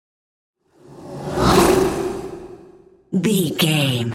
Whoosh airy creature
Sound Effects
Atonal
ominous
eerie